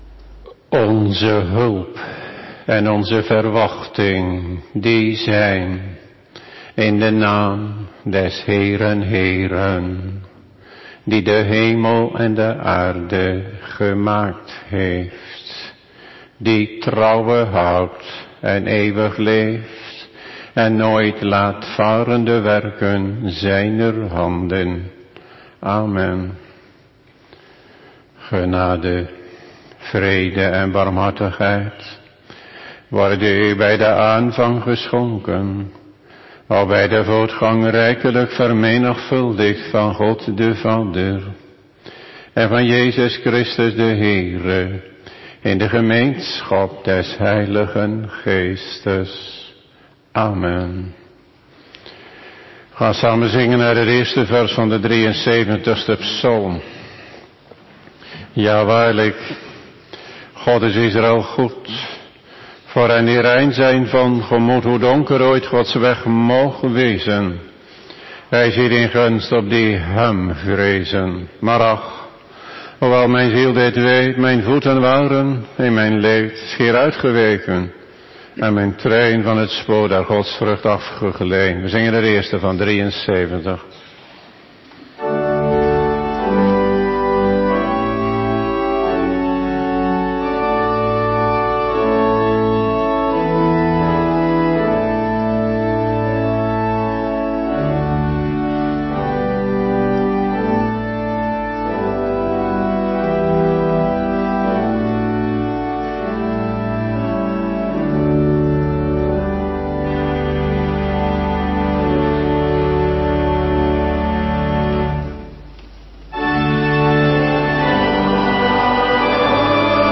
Bijbellezing